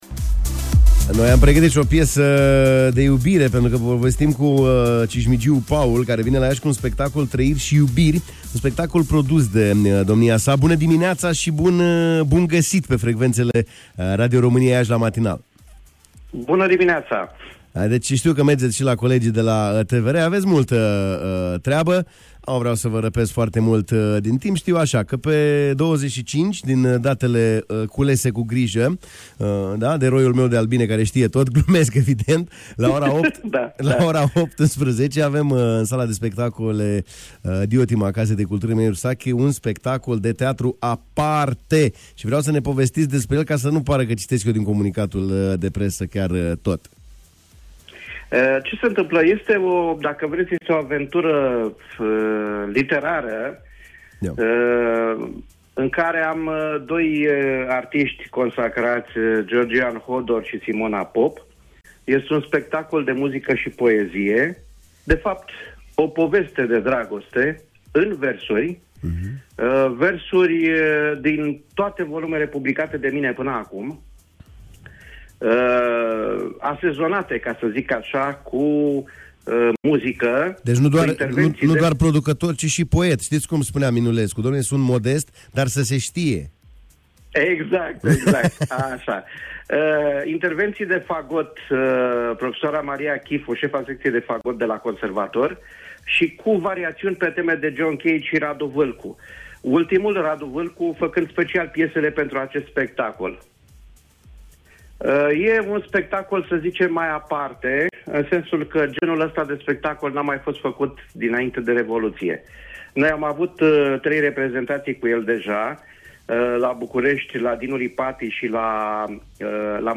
în direct la matinal